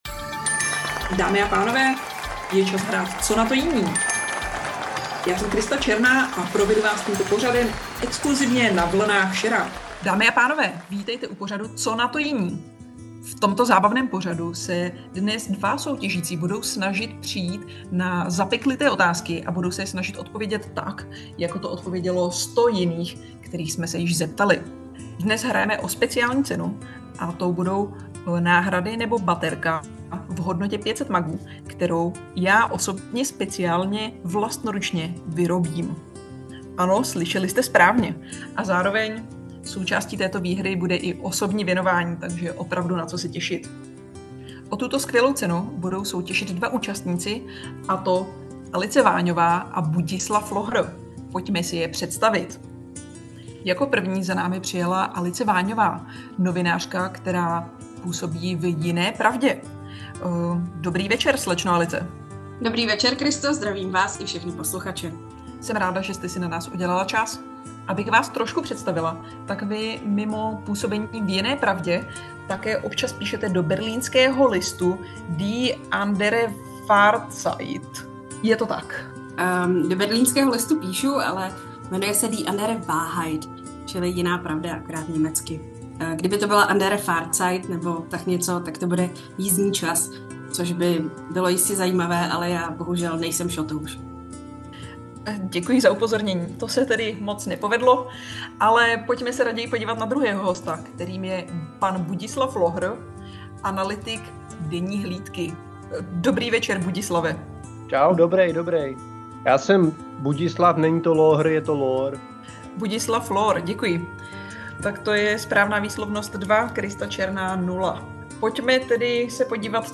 soutěžním pořadu